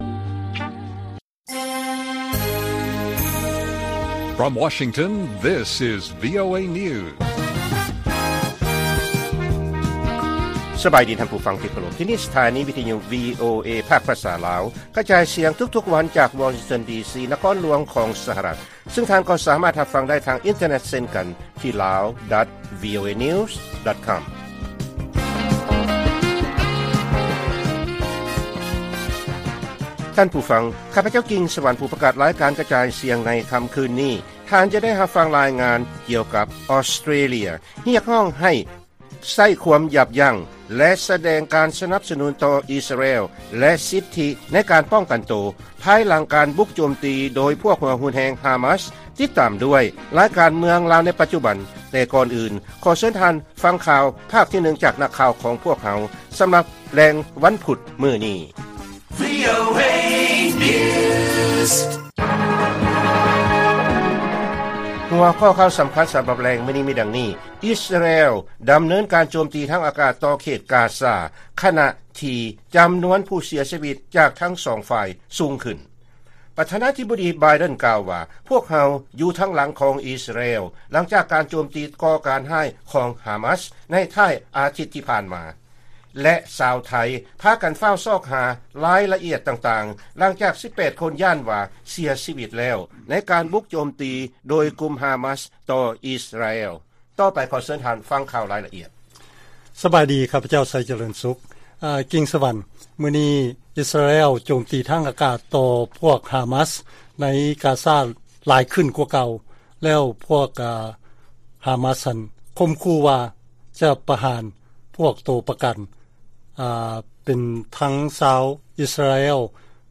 ລາຍການກະຈາຍສຽງຂອງວີໂອເອ ລາວ: ອິສຣາແອລ ດຳເນີນການໂຈມຕີທາງອາກາດ ຕໍ່ເຂດກາຊາ ຂະນະທີ່ນຳນວນຜູ້ເສຍຊີວິດ ຈາກທັງສອງຝ່າຍສູງຂຶ້ນ